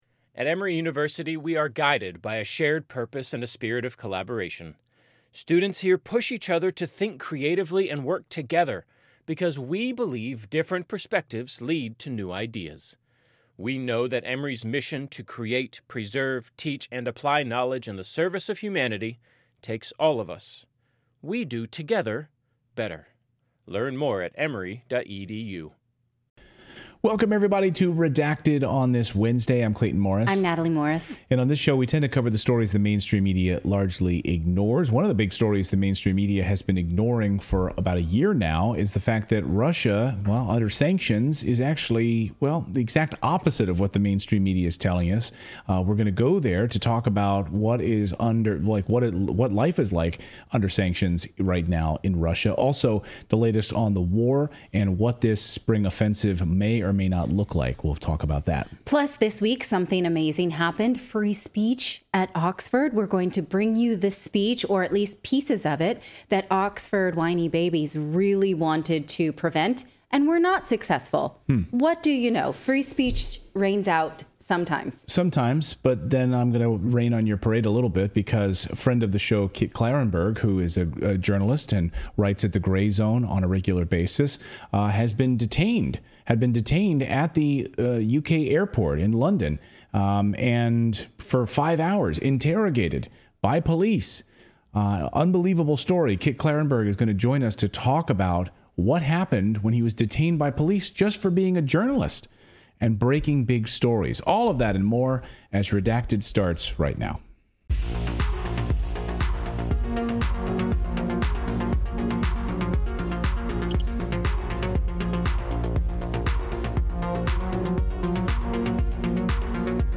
11:03 PM Headliner Embed Embed code See more options Share Facebook X Subscribe Former U.N. weapons inspector Scott Ritter joins Redacted to talk about his month long trip to Russia and what he saw in the stores, streets, and among the people.